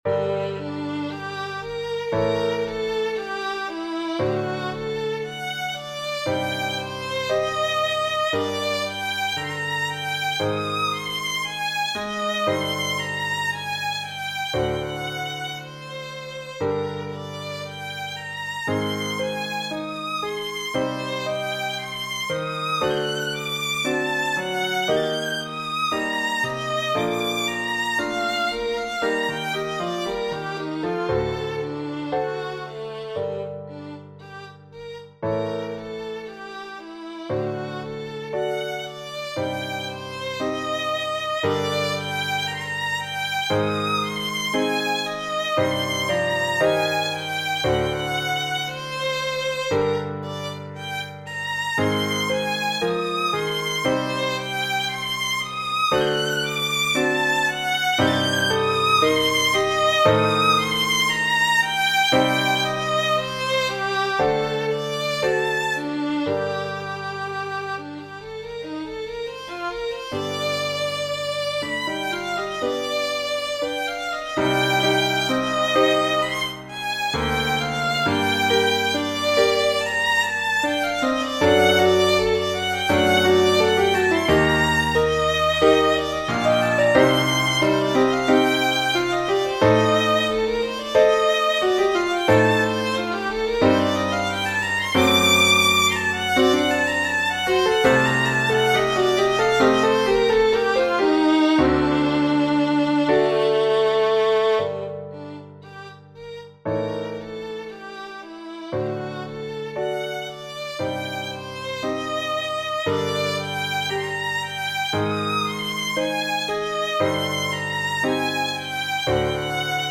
- Chamber Music - Young Composers Music Forum
Prelude for violin, need feedback from violinist :3.